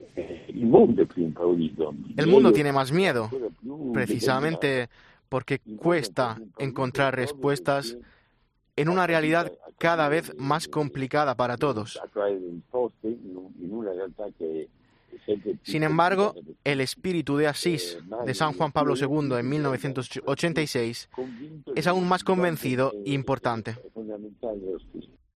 ECCLESIA tuvo la oportunidad de hablar el pasado mes de octubre con el arzobispo de Bolonia sobre varios temas antes del Encuentro Internacional organizado por Sant'Egidio en Roma